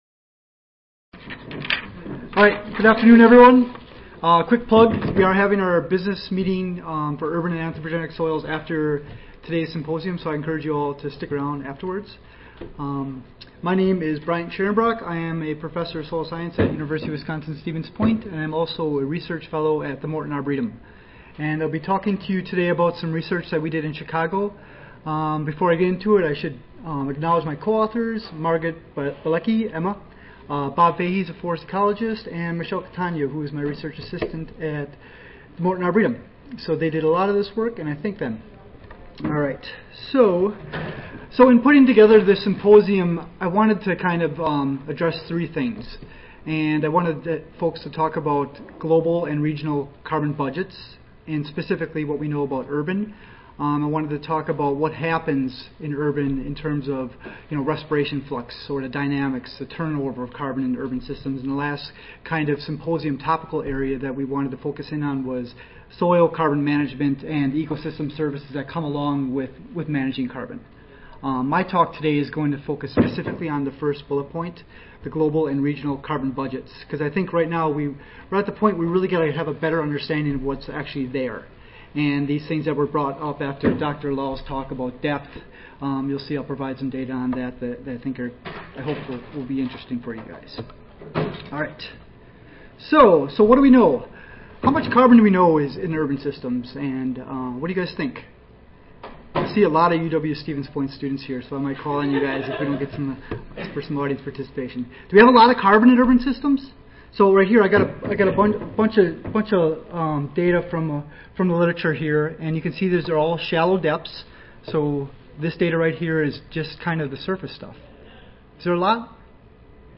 The Morton Arboretum Audio File Recorded Presentation